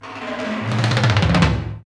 Index of /90_sSampleCDs/Roland - Rhythm Section/TOM_Rolls & FX/TOM_Tom Rolls
TOM TOM R09R.wav